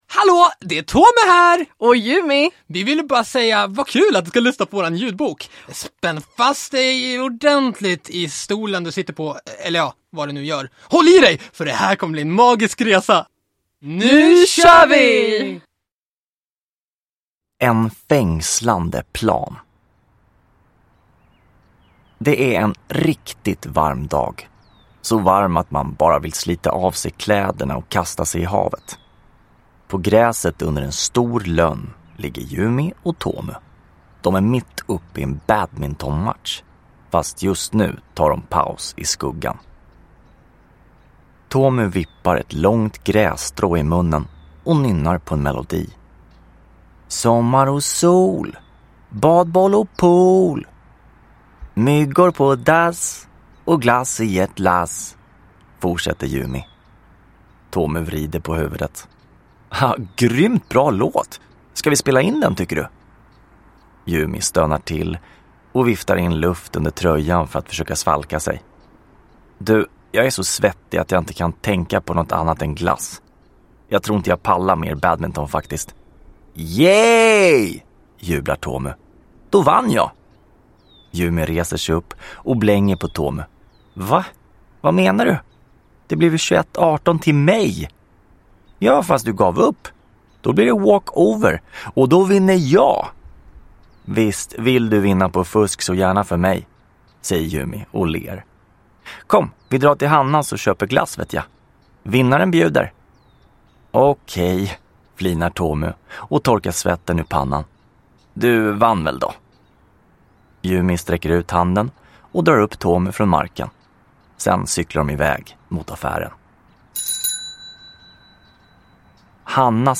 En fängslande plan – Ljudbok